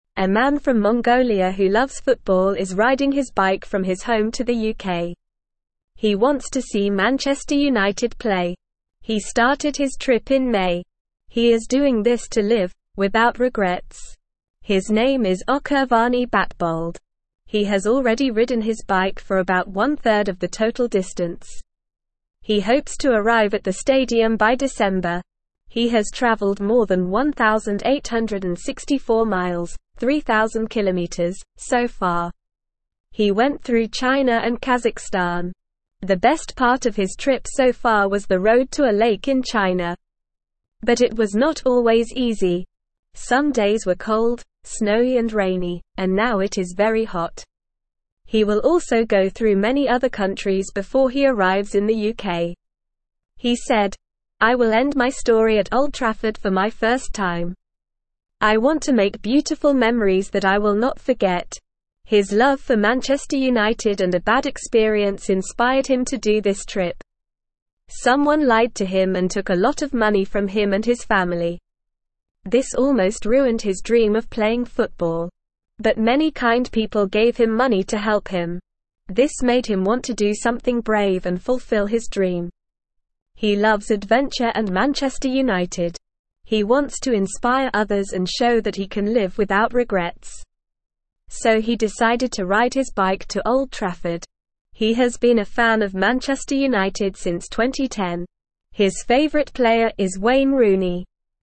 Normal
English-Newsroom-Beginner-NORMAL-Reading-Man-Rides-Bike-to-UK-for-Football-Game.mp3